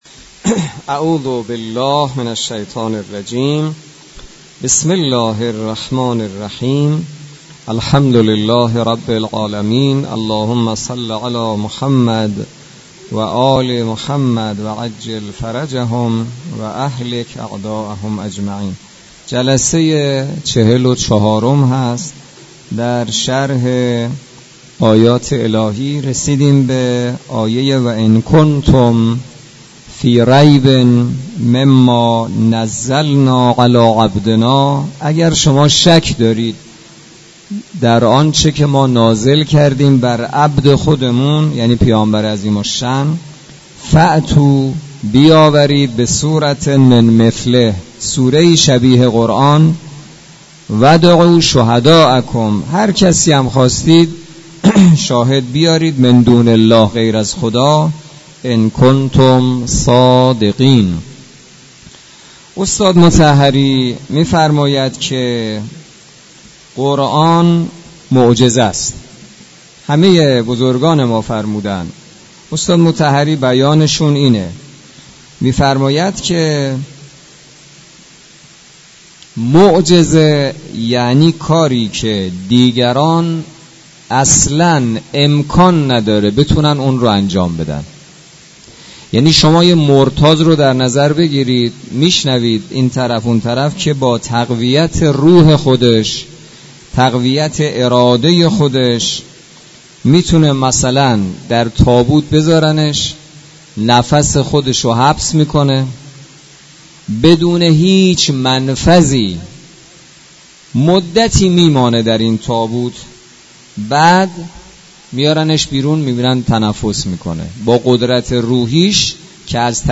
برگزاری بیست و هشتمین جلسه تفسیر سوره مبارکه بقره توسط امام جمعه کاشان در مسجد دانشگاه.